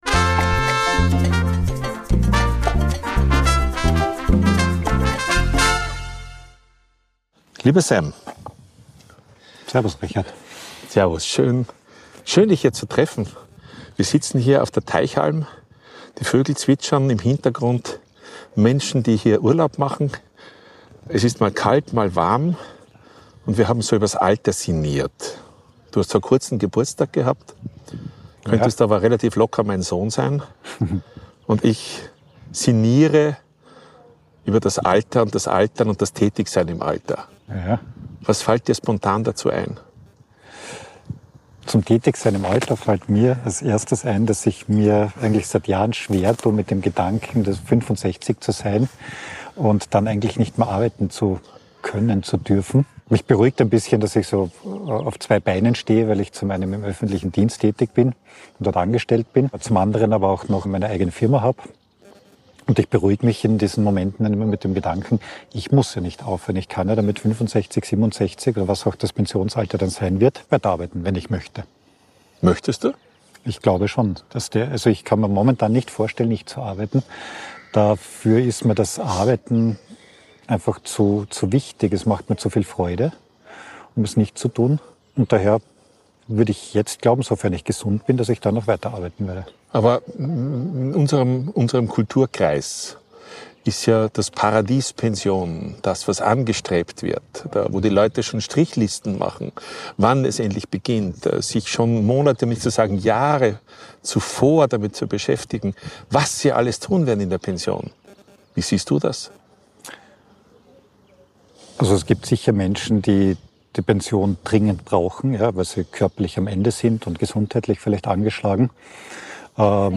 Ein ruhiges, ehrliches Gespräch über Lebensfreude, Arbeit – und das gute Gefühl, noch nicht fertig zu sein.